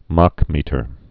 (mäkmētər)